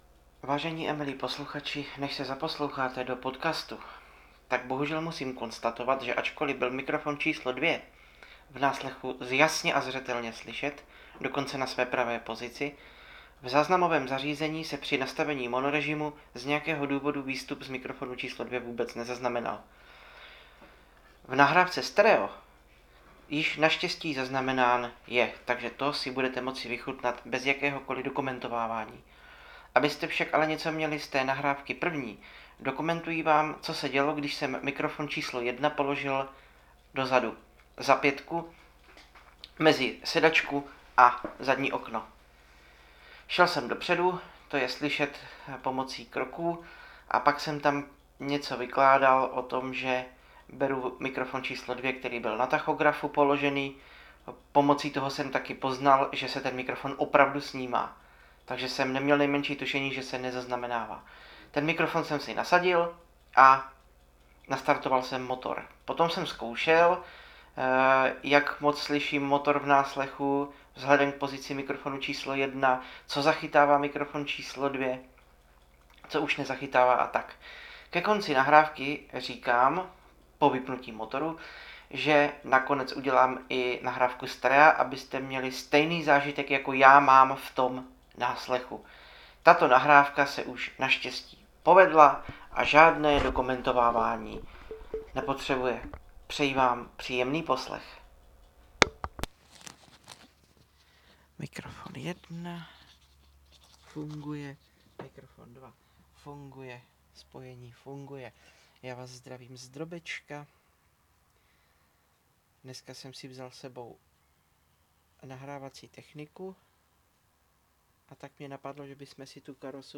Srdce nevidomého dopraváka Autobus Karosa C734.20 (Drobeček)